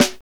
Index of /90_sSampleCDs/Northstar - Drumscapes Roland/DRM_R&B Groove/SNR_R&B Snares x